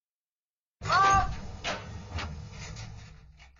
修改说明 装填弹药音效01款
321装填弹药音效01.mp3